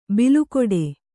♪ bilukoḍe